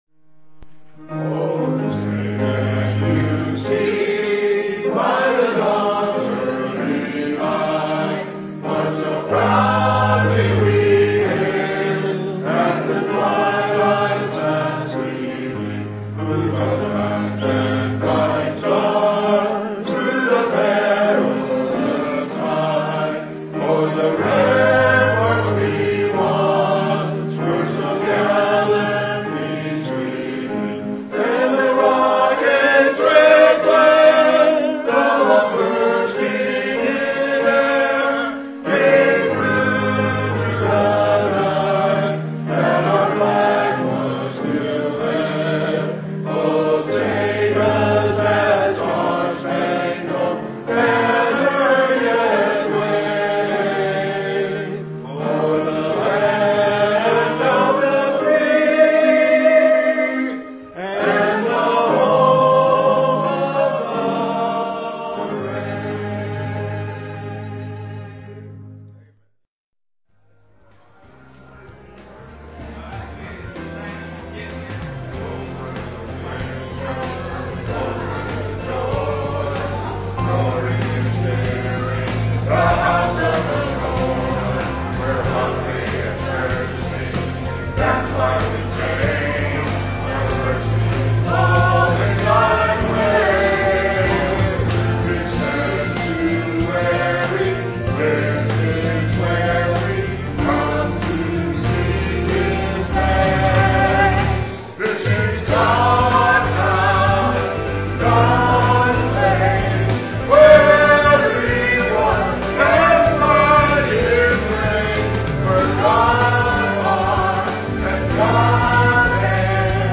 piano
digital piano.